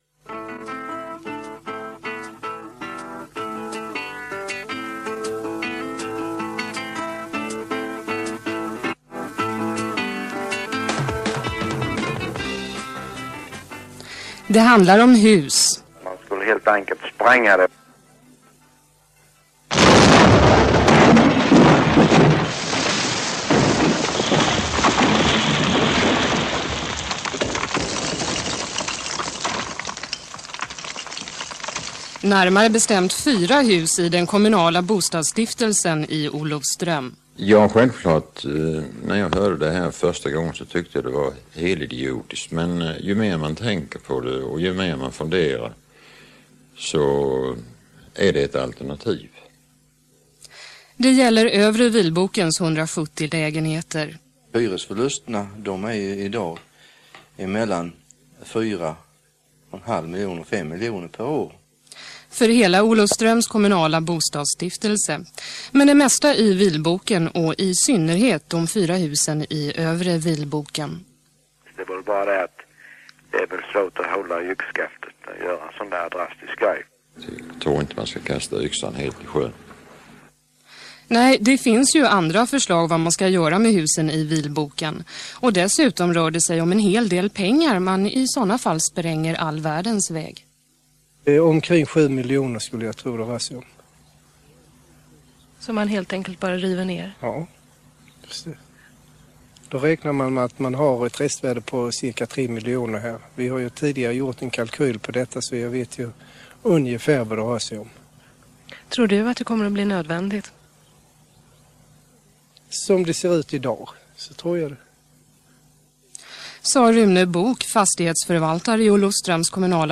Bostadsområdet Vilboken i Olofström drabbades hårt av neddragningar på Volvo. Hela huskroppar stod utan hyresgäster. 1981 fördes en intensiv diskussion om huruvida man skulle ta till rivning. Det här radioprogrammet satte känslorna i svall, inte minst på grund av de dramatiska ljudeffekterna.